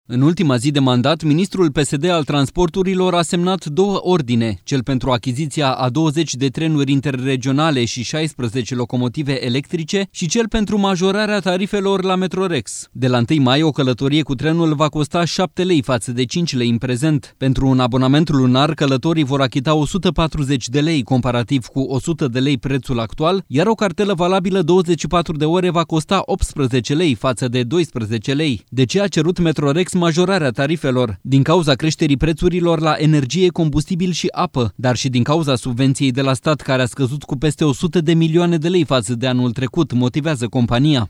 În exclusivitate pentru Europa FM, Radu Miruță a explicat că va verifica atât motivele care au stat la baza creșterii tarifelor, cât și modul de utilizare al banilor la Metrorex.